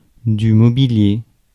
Ääntäminen
Synonyymit meuble Ääntäminen France: IPA: [mɔ.bi.lje] Haettu sana löytyi näillä lähdekielillä: ranska Käännös Adjektiivit 1. mobiliario {m} Substantiivit 2. muebles {m} Suku: m .